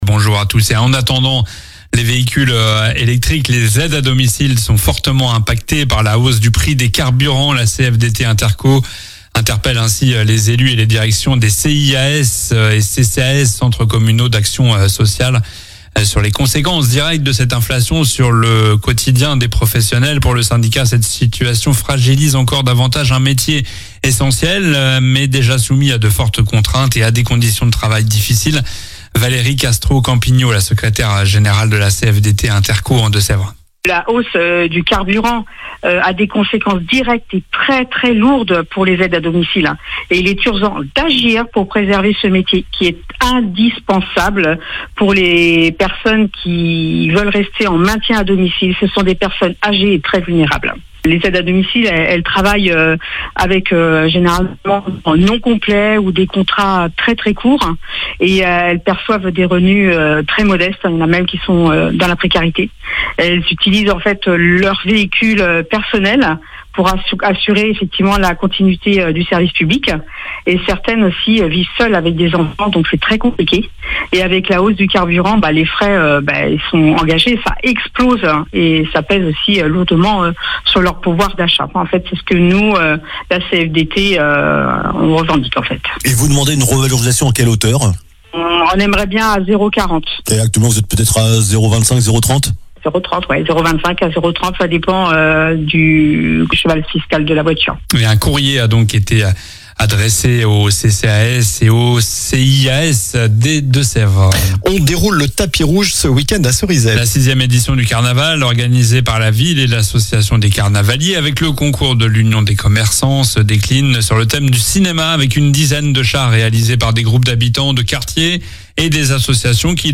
Journal du samedi 11 avril